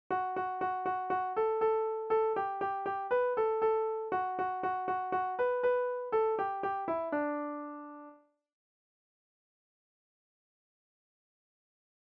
Kinderlieder: Murmeltiers Reise
Tonart: D-Dur
Taktart: 2/4
Tonumfang: große Sexte
Besetzung: vokal